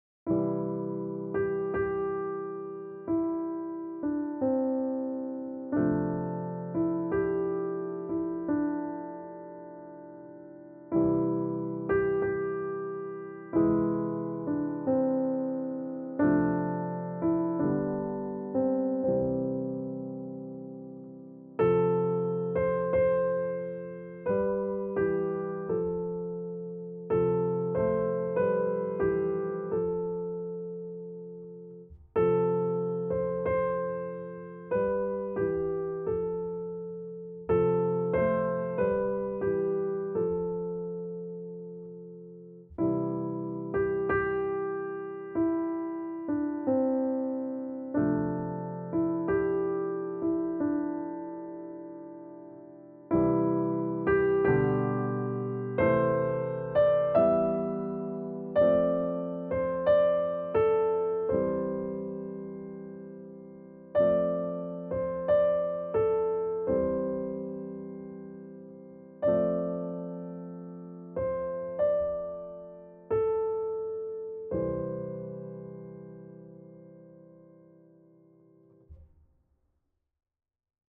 Largo z Novosvětské symfonie na klavír - videolekce a noty pro začátečníky